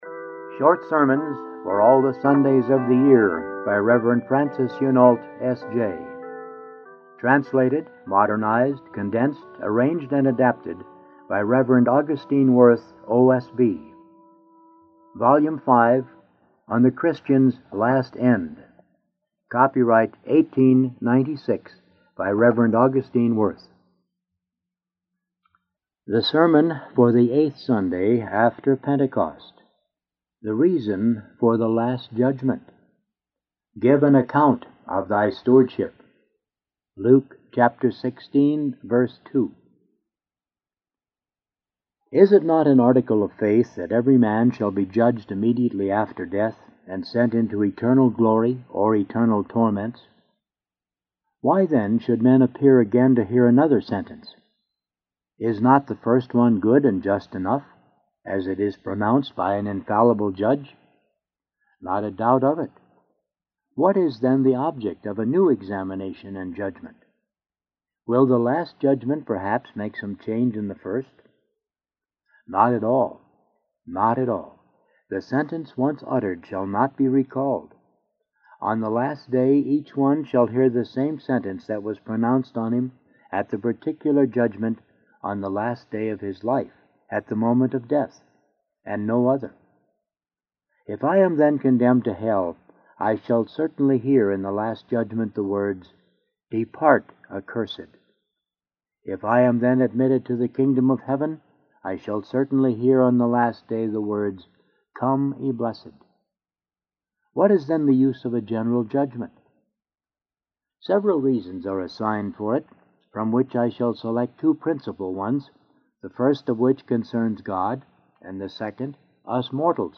Sermon: The Particular Judgment